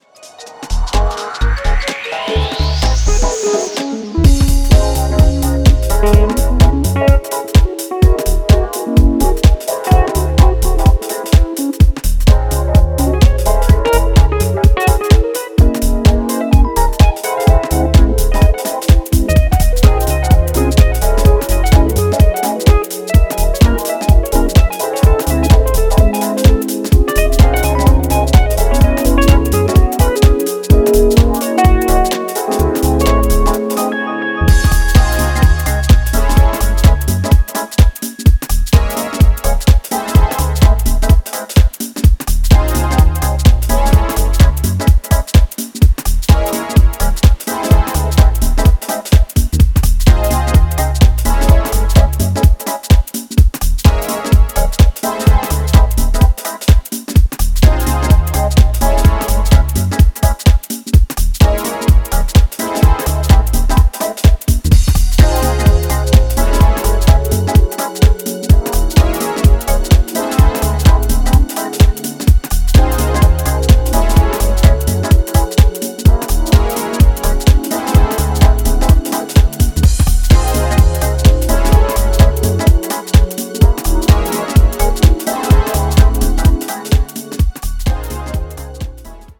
クリアなメロディーやリードギターの絡みが心地良いジャジー・ディープ・ハウス